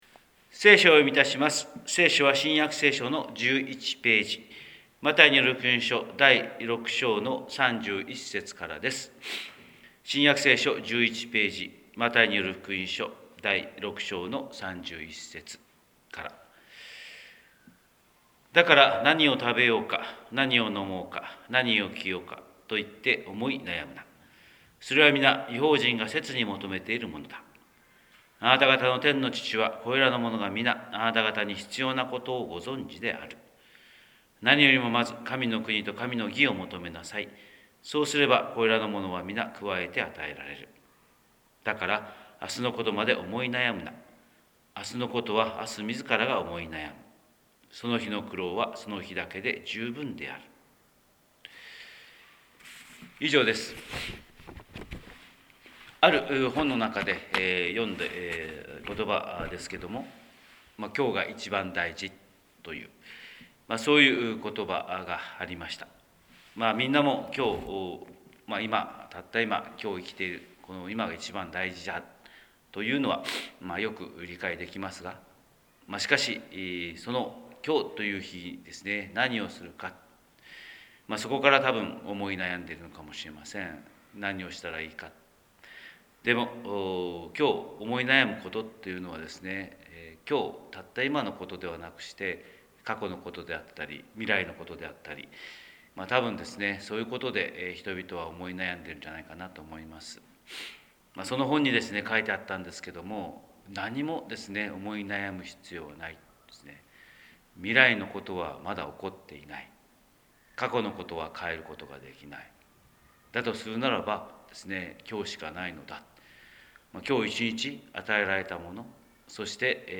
神様の色鉛筆（音声説教）: 広島教会朝礼拝250225